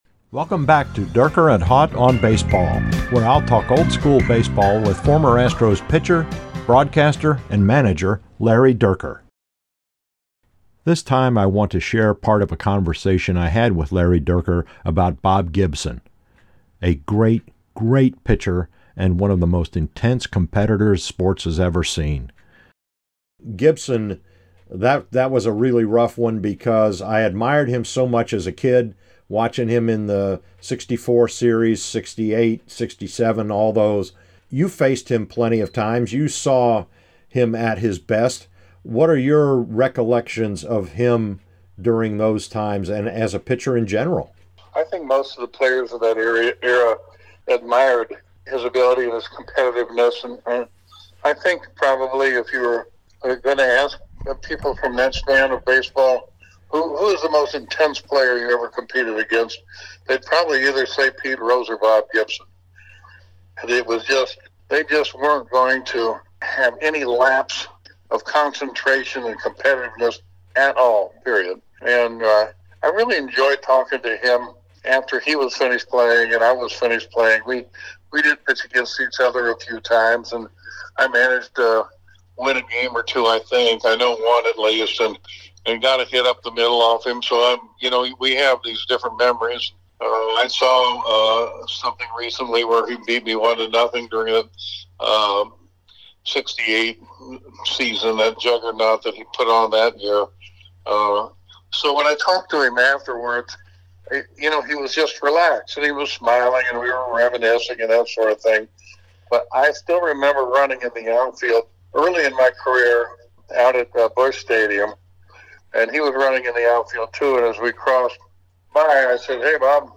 Here’s part of a conversation I had with Larry Dierker about a great pitcher and fierce competitor.